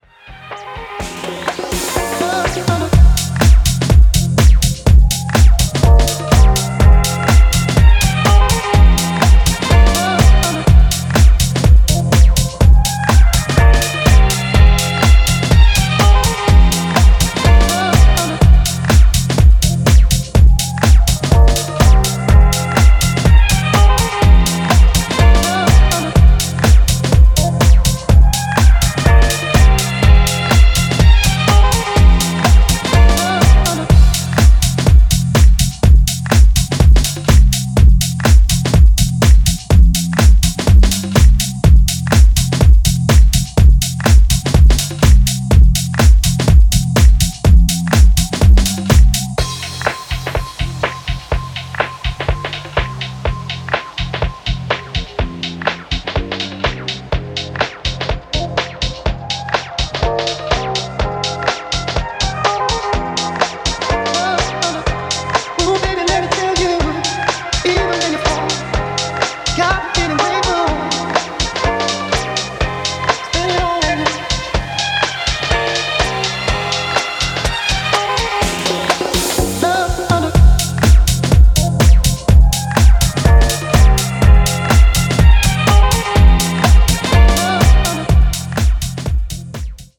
ディスコサンプルに施されたフィルターと共にビルドアップするウォームなディスコハウス！